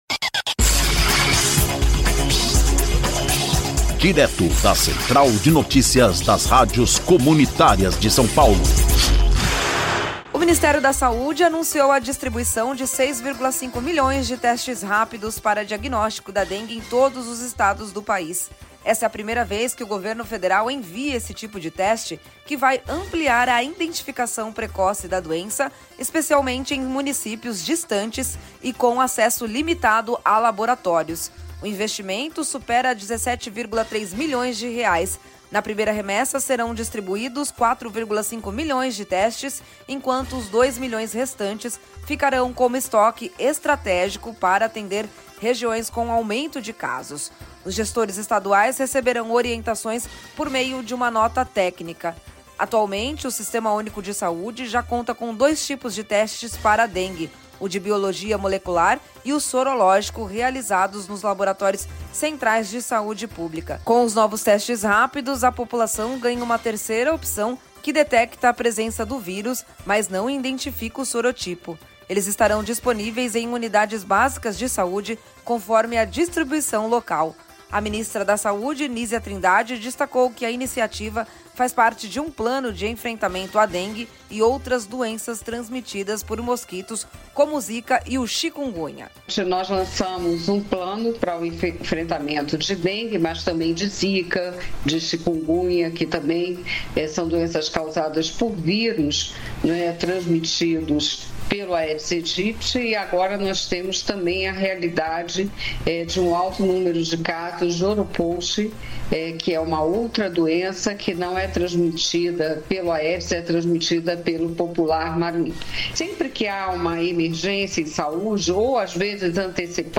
Ouça a notícia: Ministério da Saúde distribui 6,5 milhões de testes rápidos para dengue